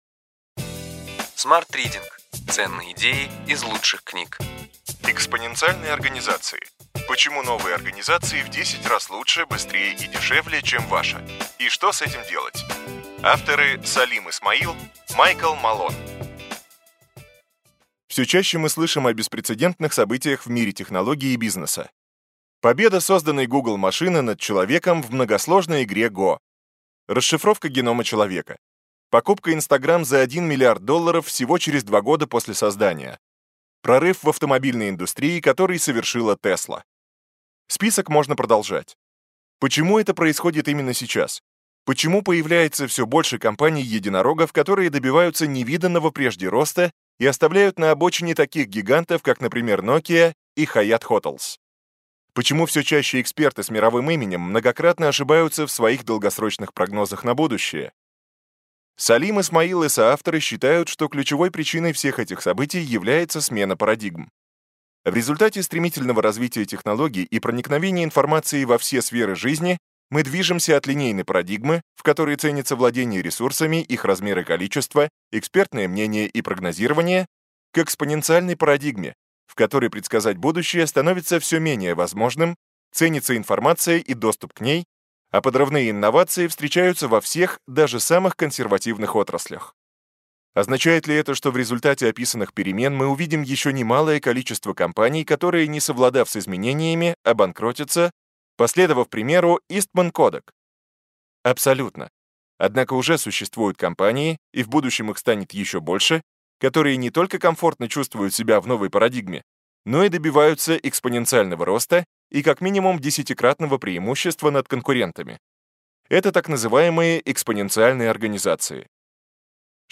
Аудиокнига Ключевые идеи книги: Экспоненциальные организации: почему новые организации в 10 раз лучше, быстрее и дешевле, чем ваша (и что с этим делать).